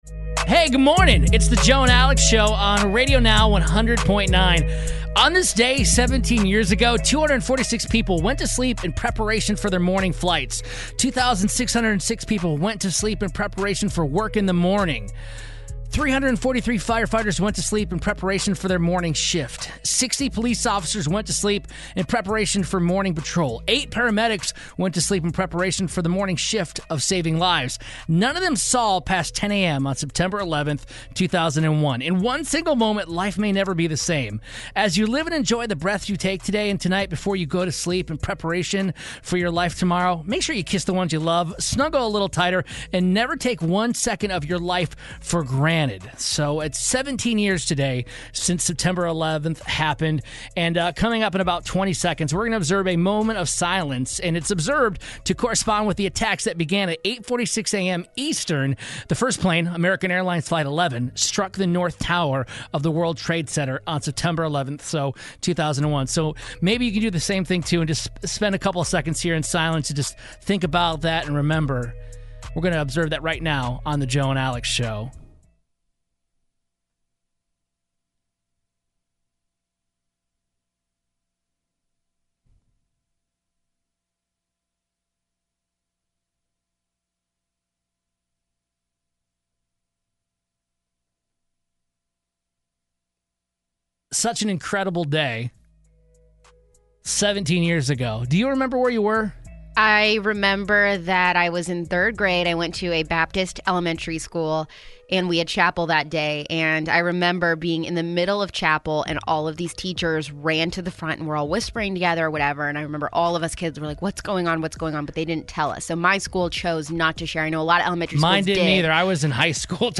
We remember where we were and how we felt the day of the 9/11 terrorist attacks and take a moment of silence to honor all of those whose lives were lost on that day.